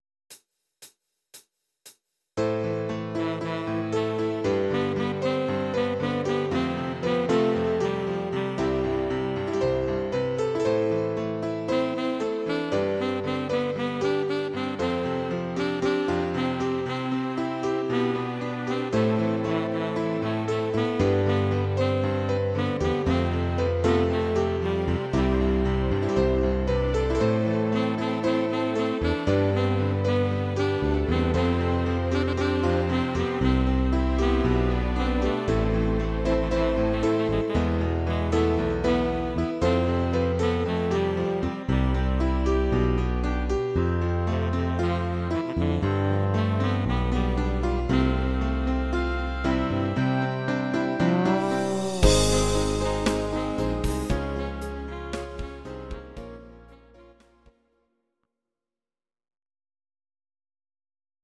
Audio Recordings based on Midi-files
Pop, 2000s